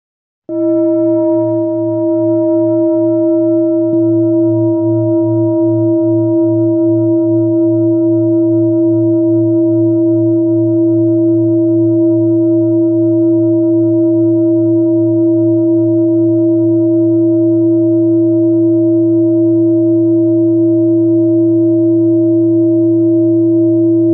High Quality Peter Hess Singing Bowls, Hand Hammered Clean Finishing L, Select Accessories , A bowl used for meditation and healing, producing a soothing sound that promotes relaxation and mindfulness
Singing Bowl Ching Lu Kyogaku
Material 7 Metal Bronze